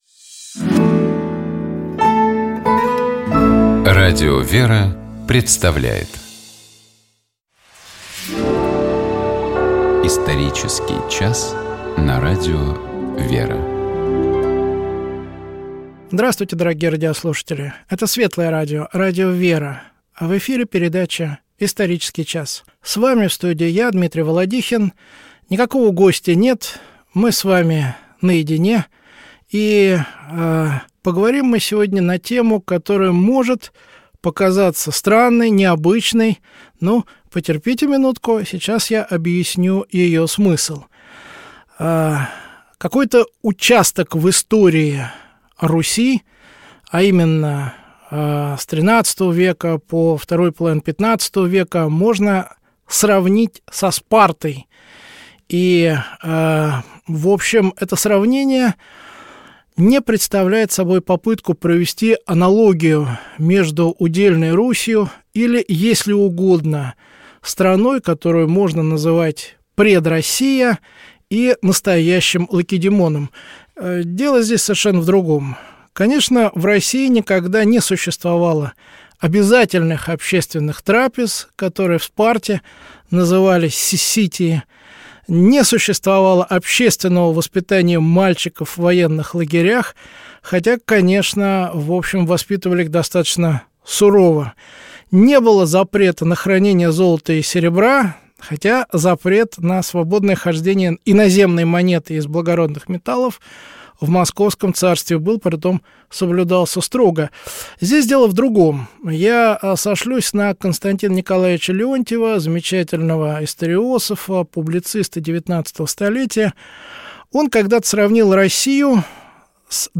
Никакого гостя нет, мы с вами наедине и поговорим мы сегодня на тему, которая может показаться странной, необычной, но потерпите минутку, сейчас я объясню ее смысл.